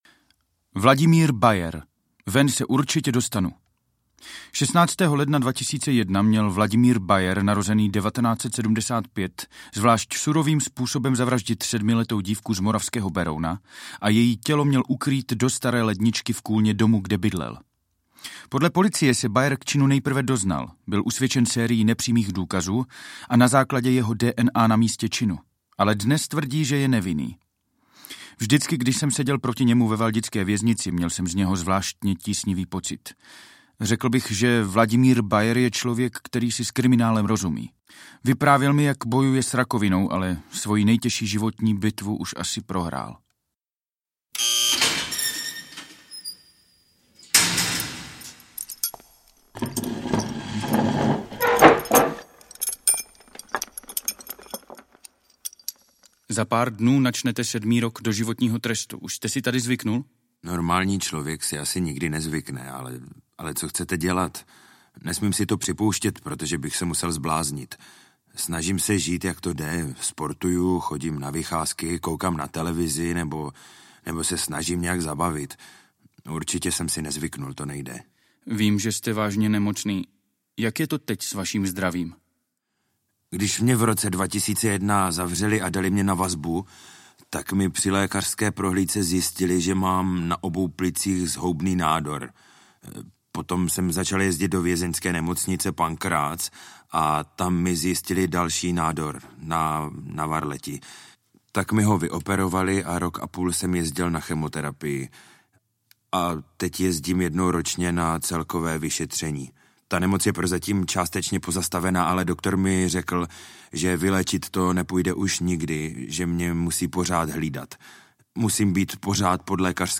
Doživotí - autentické výpovědi doživotních vězňů audiokniha
Ukázka z knihy